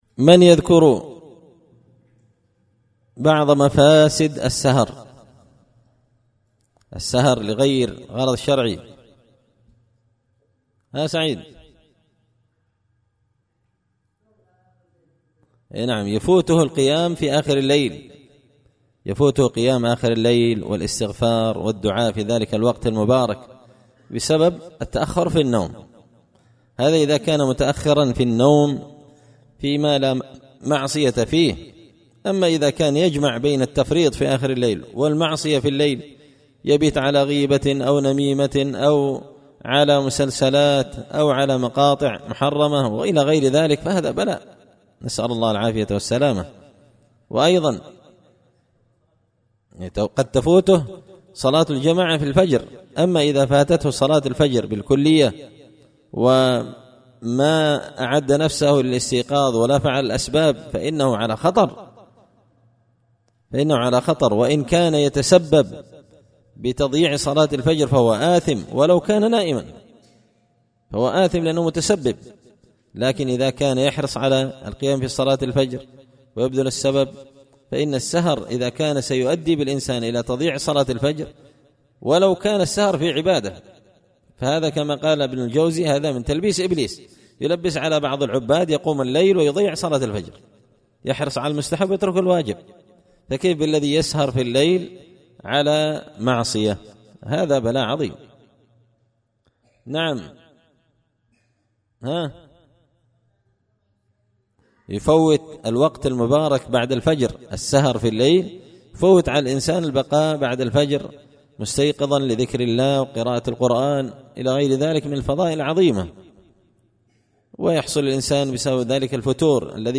إمتاع النظر بأحكام السمر والسهر ـ الدرس الرابع عشر